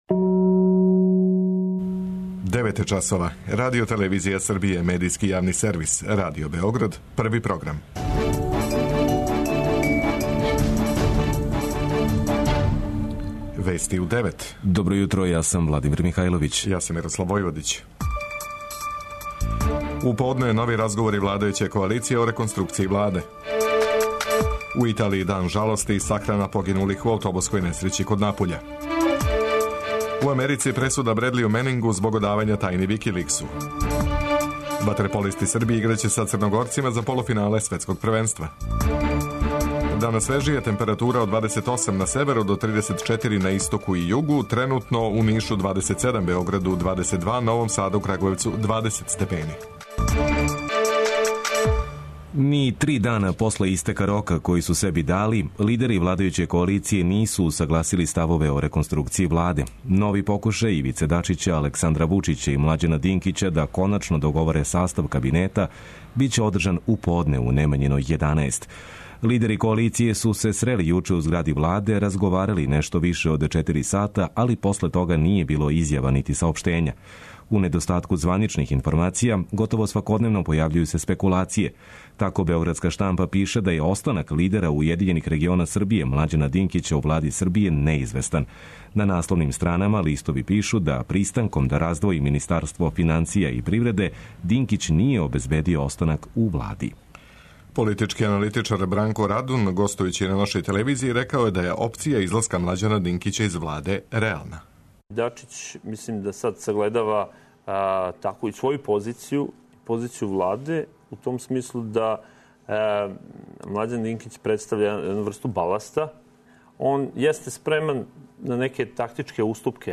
преузми : 10.22 MB Вести у 9 Autor: разни аутори Преглед најважнијиx информација из земље из света.